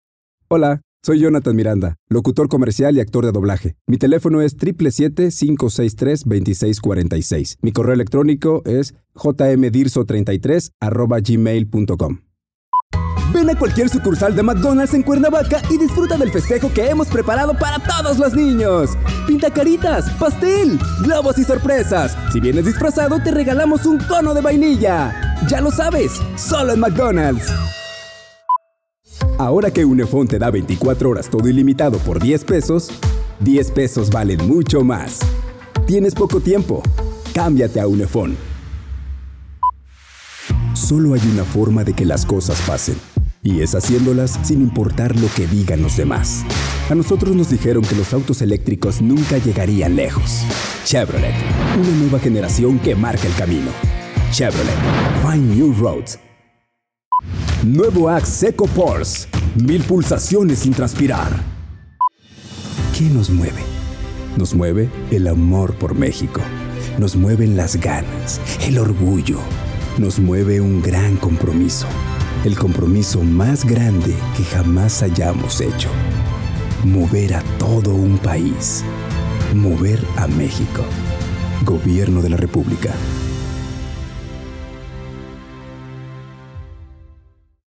西班牙语男声
低沉|激情激昂|大气浑厚磁性|沉稳|娓娓道来|科技感|积极向上|时尚活力|神秘性感|调性走心|感人煽情|素人